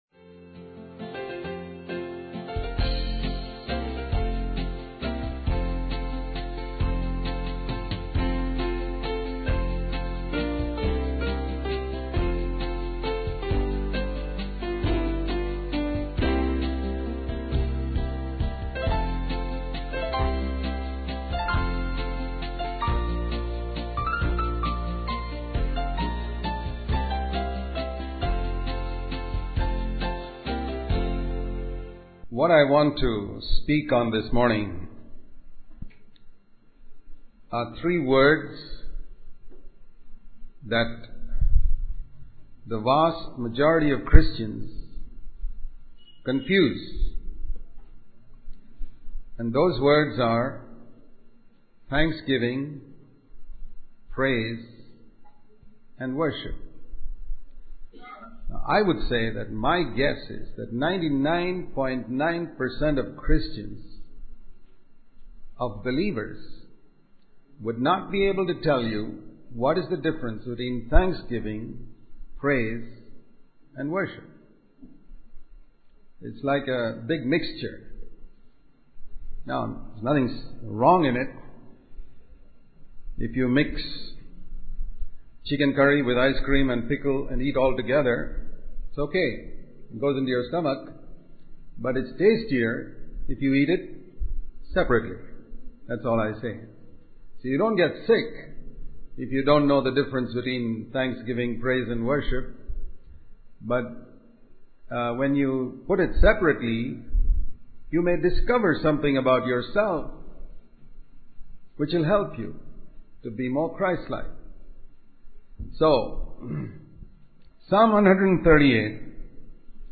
This sermon emphasizes the importance of understanding the distinctions between thanksgiving, praise, and worship. It highlights the significance of expressing gratitude, offering praise, and surrendering in worship to God.